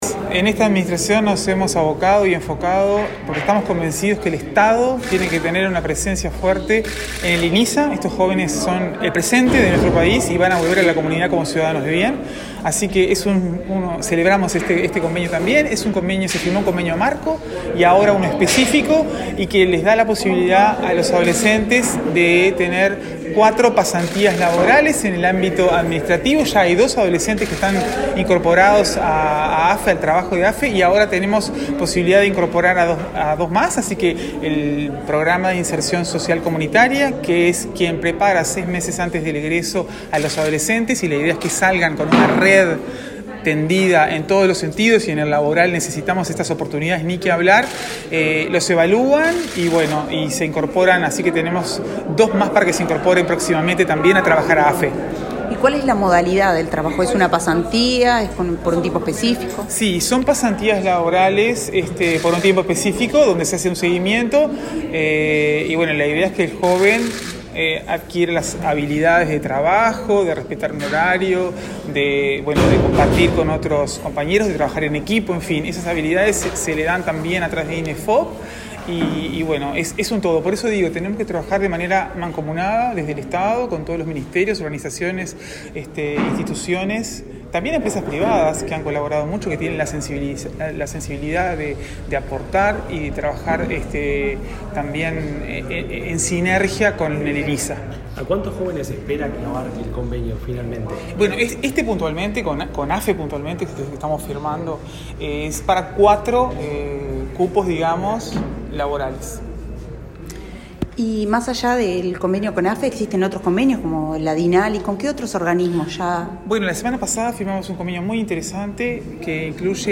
Declaraciones de la presidenta de Inisa a la prensa
La presidenta del Instituto Nacional de Inclusión Social Adolescente (Inisa), Rosanna de Olivera, dialogó con la prensa antes de firmar un convenio